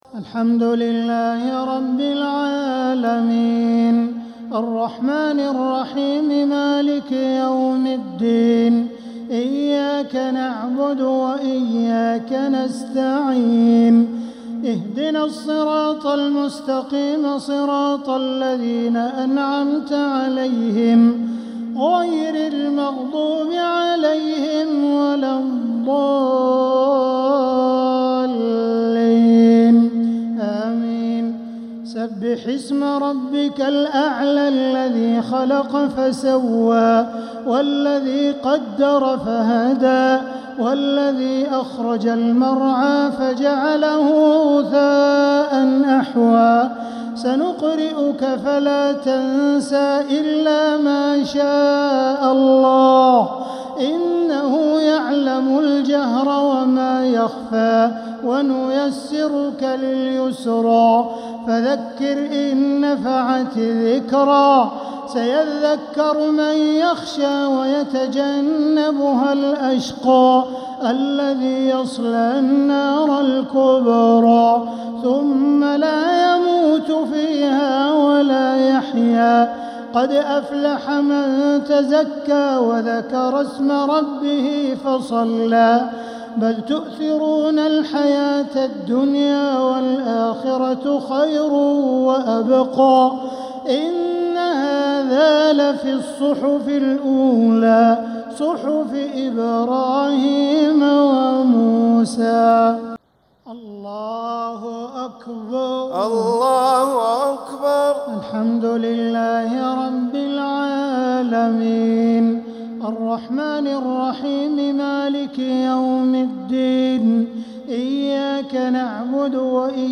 صلاة الشفع و الوتر ليلة 7 رمضان 1447هـ | Witr 7th night Ramadan 1447H > تراويح الحرم المكي عام 1447 🕋 > التراويح - تلاوات الحرمين